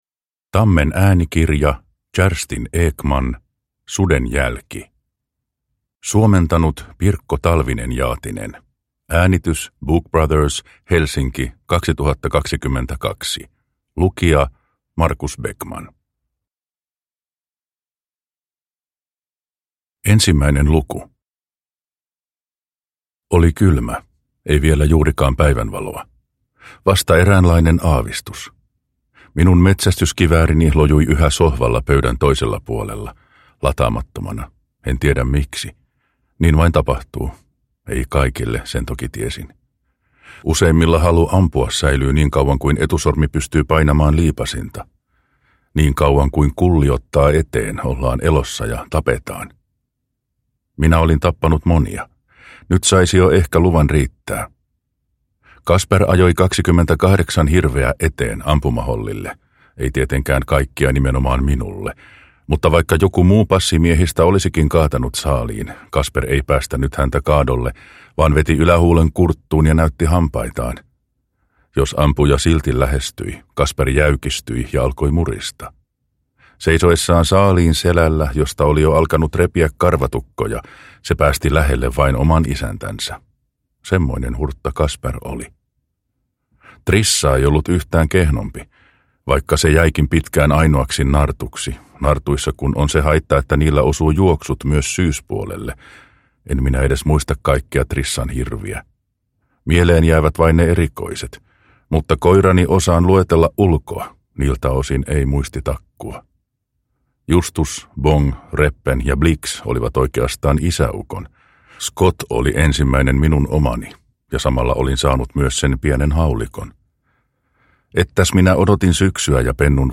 Suden jälki – Ljudbok – Laddas ner